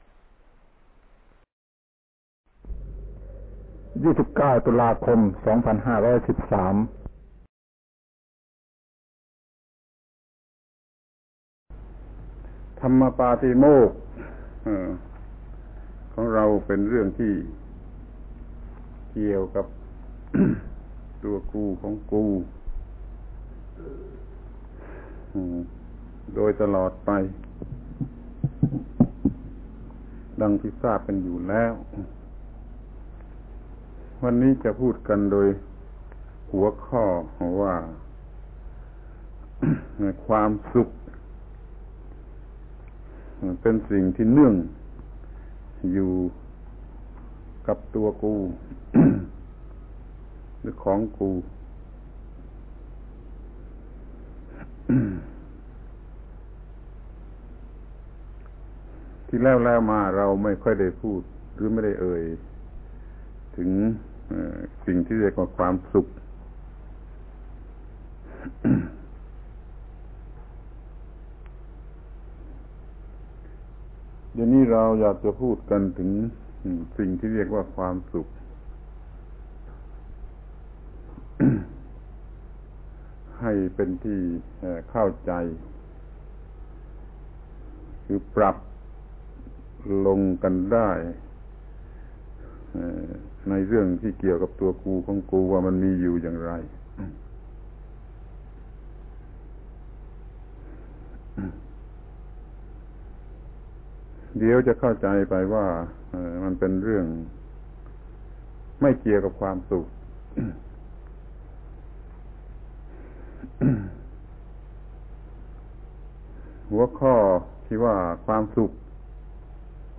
พระธรรมโกศาจารย์ (พุทธทาสภิกขุ) - อบรมพระที่หน้าโรงหนัง เรื่อง โอวาทปาฏิโมกข์ ปี 2513 ครั้งที่30/ความสุขเป็นสิ่งเนื่องจากตัวกู(ไม่จบแผ่นไม่พอ)